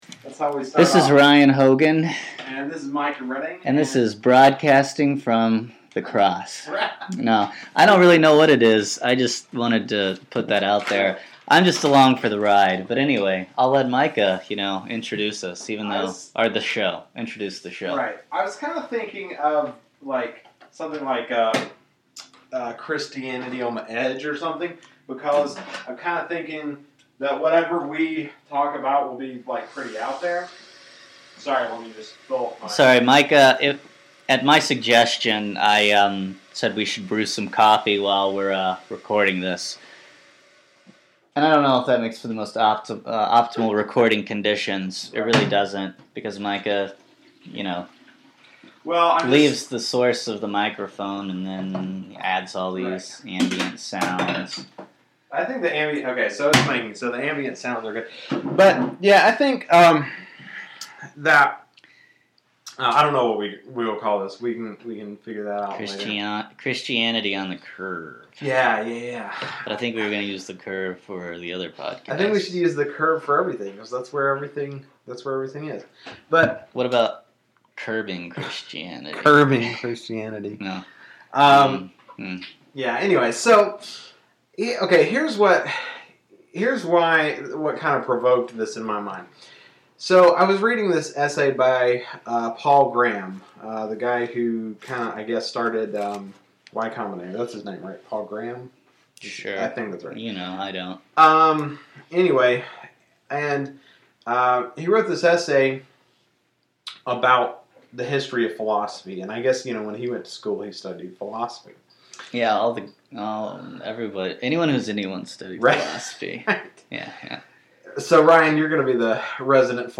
pour coffee loudly